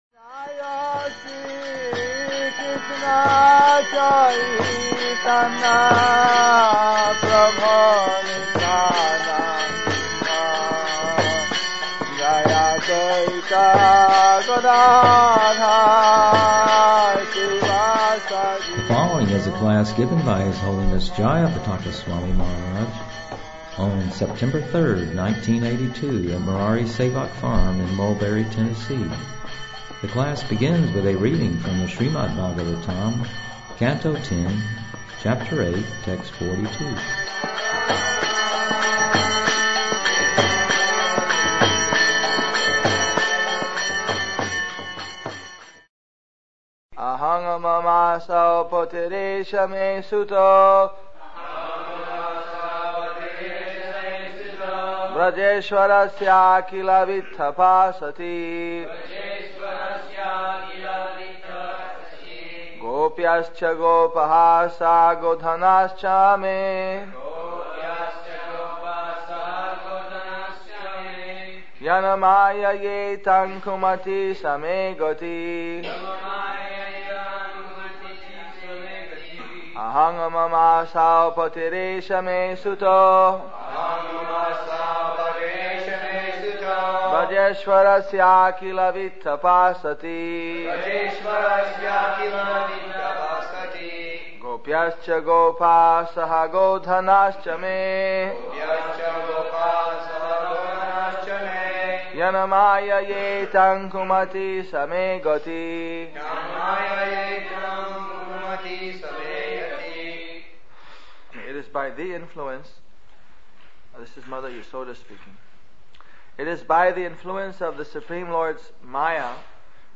The following is a class
1982 in Murari Sevaka Farm, Lynch-burg, Tennessee, USA. The class begins with Srimad Bhagavatam 10/8/42